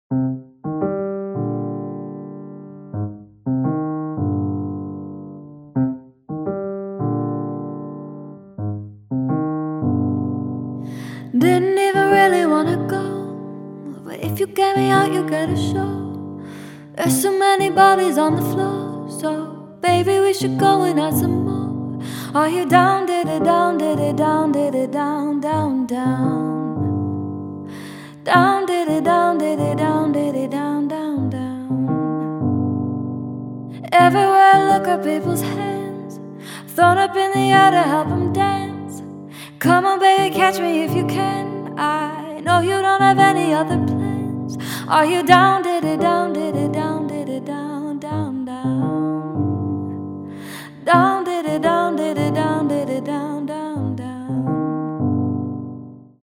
• Качество: 256, Stereo
женский голос
пианино
красивый женский голос
из рекламы